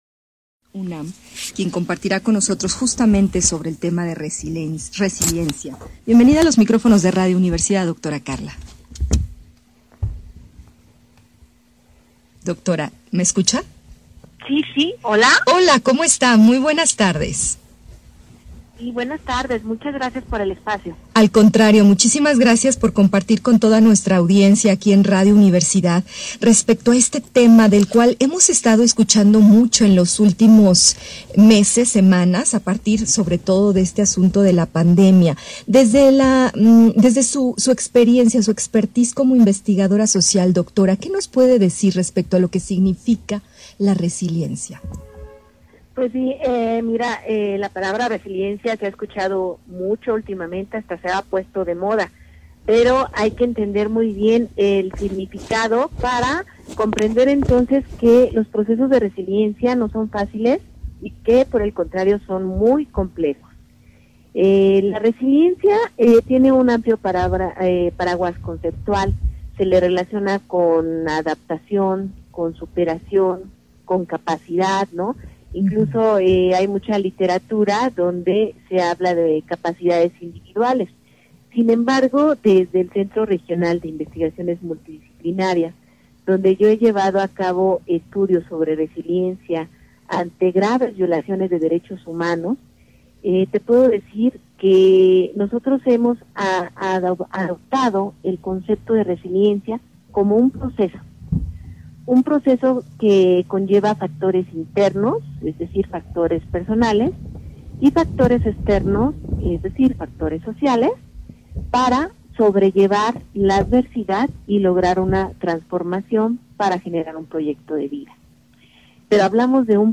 Entrevista
Radio y televisión de la Universidad Autónoma de San Luis Potosí, lunes 29 de junio de 2020.